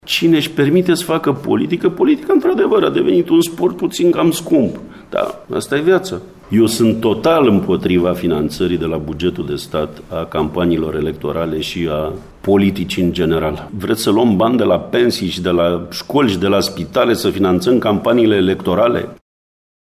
Finanţarea campaniei electorale de la bugetul de stat trebuia făcută cu mult timp înainte, a declarat, săptămâna trecută la Reşiţa, vicepreşedintele Partidului Naţional Liberal, Sorin Frunzăverde.